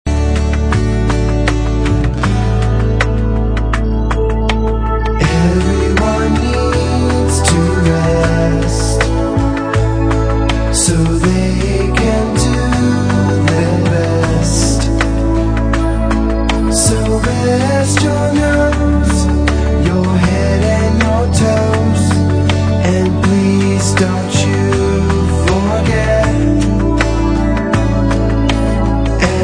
Quiet Song for Preparing Children for Naps or Bedtime